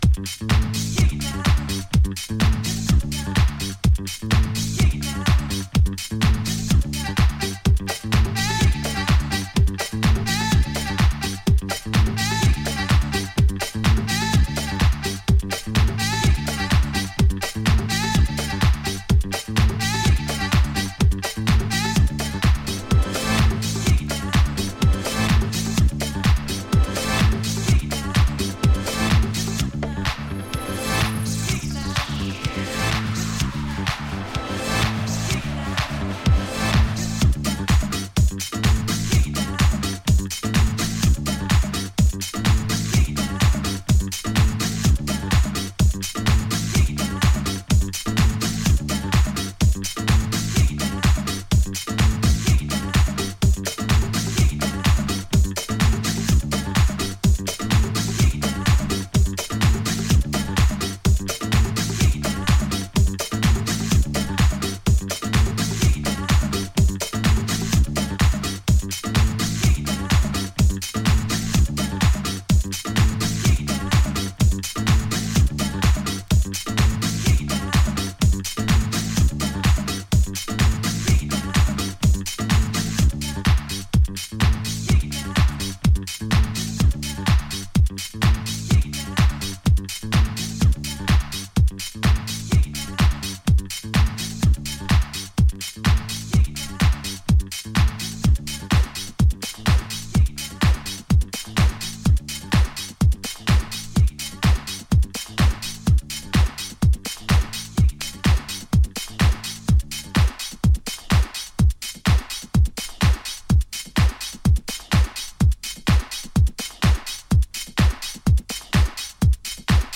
享楽的かつアンダーグラウンドな佇まいの全4曲